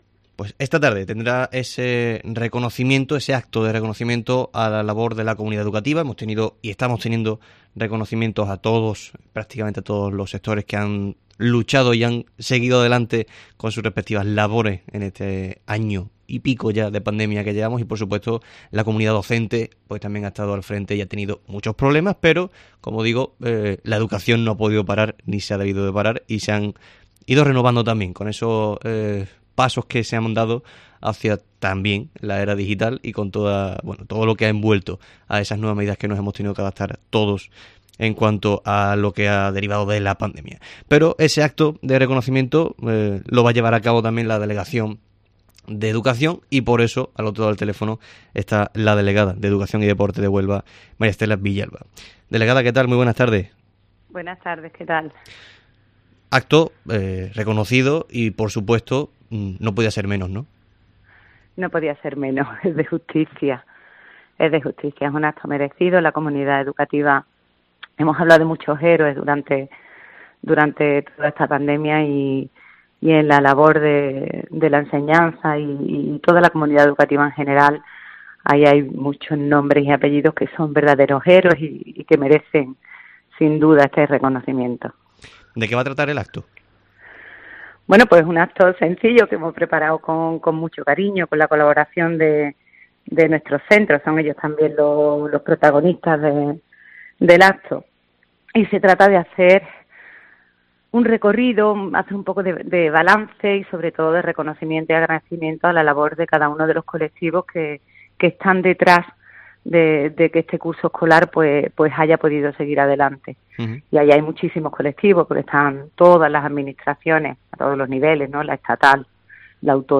Estela Villalba, delegada de Educación y Deporte de la Junta en Huelva